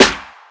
Party Snare.wav